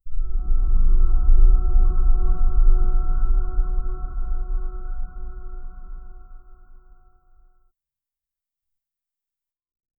soft-airy-tail--echo-hgzf3fac.wav